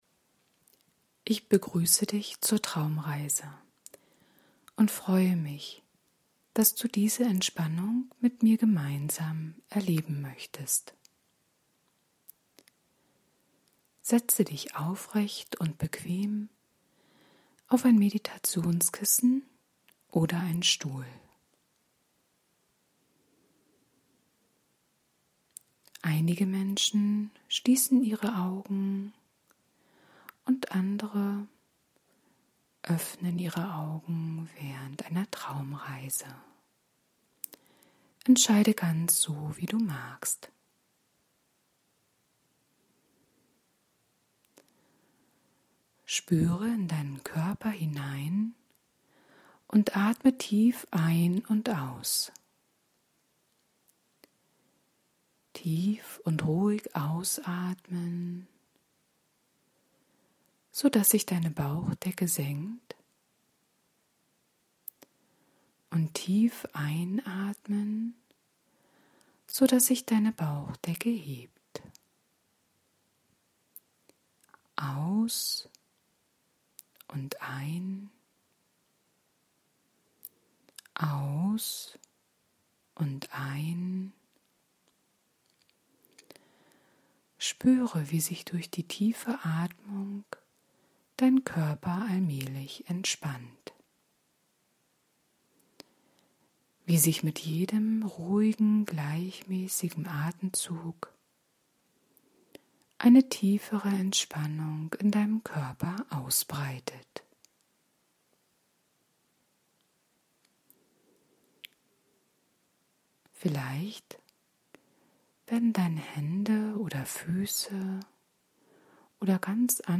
Trancen zur Entspannung
Geführter Spaziergang zum Flußufer, zu einem Baum, um dort auszuruhen und Geräusche und Gerüche der Natur wahrzunehmen.